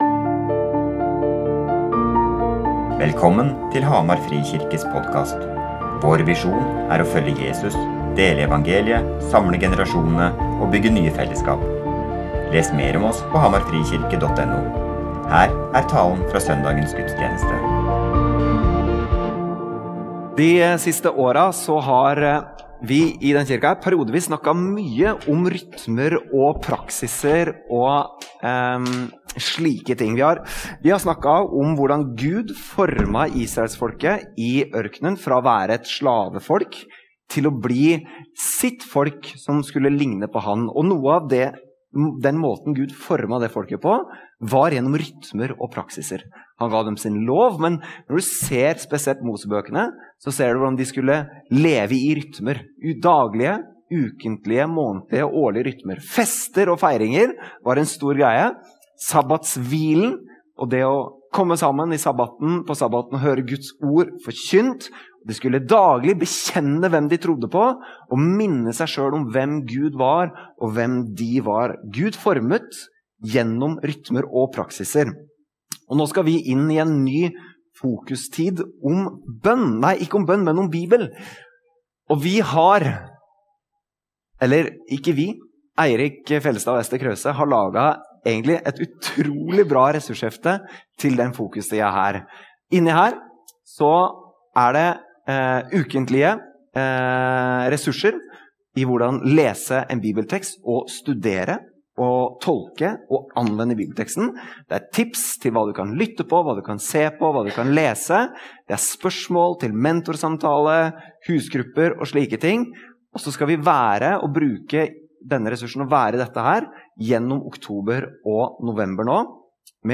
Gudstjenesten